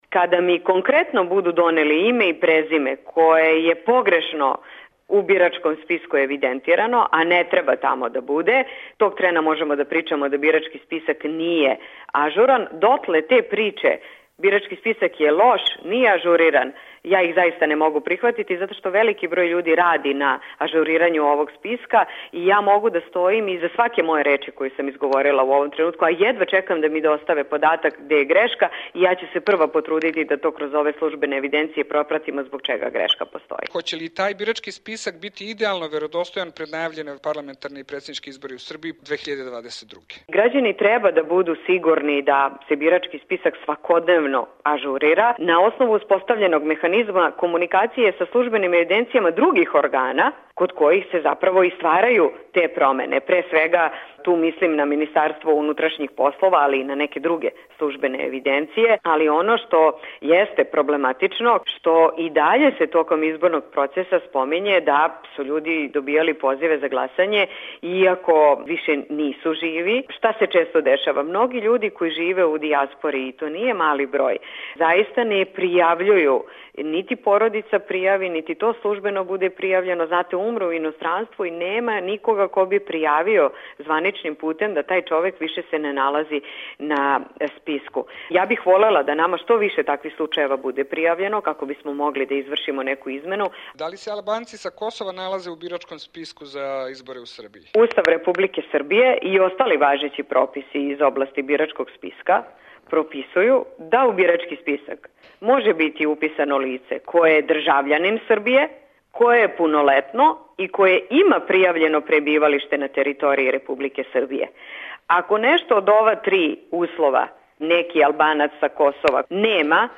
Birački spisak sređeniji je nego ikada i jedna je od najažurnijih baza podataka u Srbiji, izjavila je Marija Obradović, ministarka državne uprave i lokalne samouprave u Vladi Srbije u intervjuu za RSE.